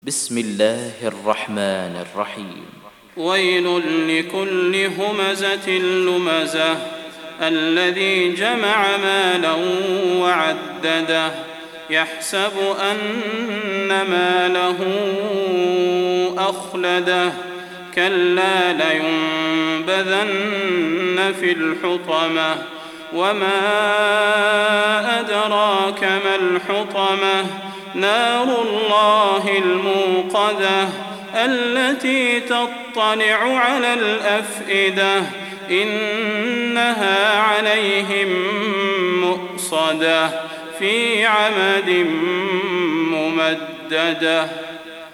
Surah Al-Humazah سورة الهمزة Audio Quran Tarteel Recitation
Surah Repeating تكرار السورة Download Surah حمّل السورة Reciting Murattalah Audio for 104. Surah Al-Humazah سورة الهمزة N.B *Surah Includes Al-Basmalah Reciters Sequents تتابع التلاوات Reciters Repeats تكرار التلاوات